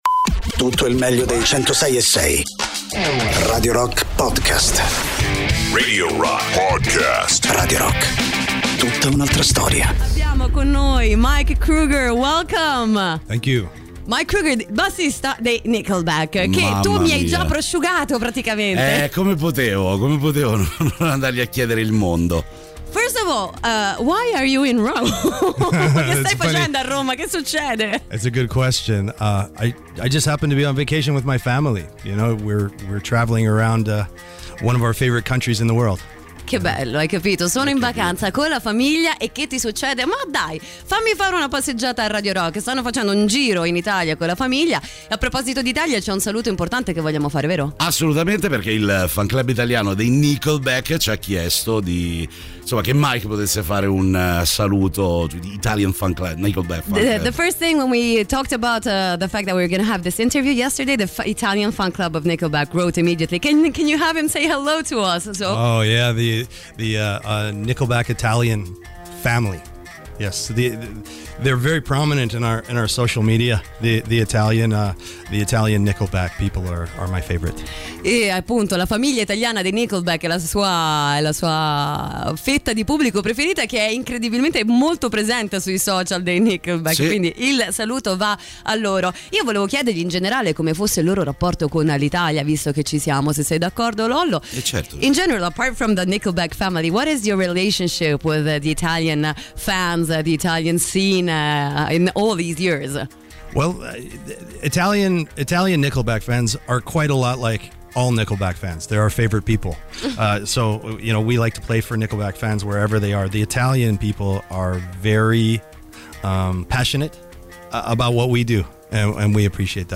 Interviste: Mike Kroeger (25-09-22)